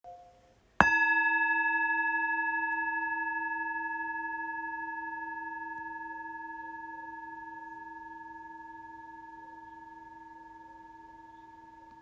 Lyssna på vibrationerna från den 14 cm stora tibetanska Chö-Pa-skålen med en klubba med en diameter på 28 mm:
tintement-bol-chopa14.m4a